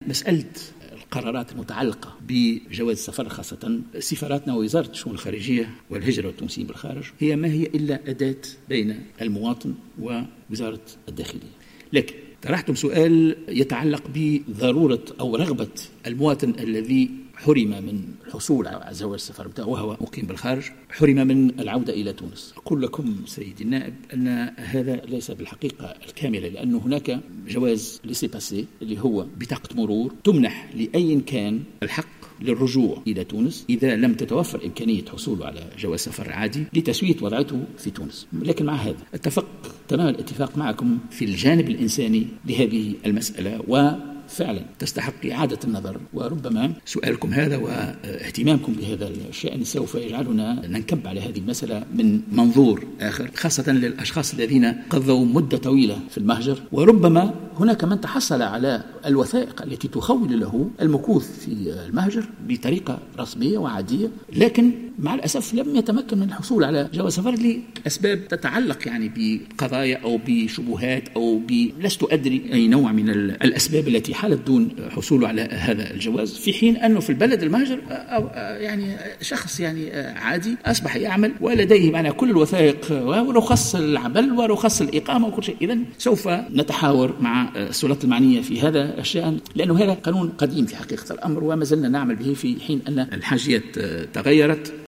قال وزير الشؤون الخارجية والهجرة والتونسيين بالخارج، عثمان الجرندي، في ردّه على سؤال النائب مبروك كورشيد، بخصوص حرمان تونسيين في الخارج من الحصول على جوازات سفرهم، إن هناك ما يُسمّى ببطاقة المرور التي تُمنح لأي مواطن يريد العودة إلى تونس.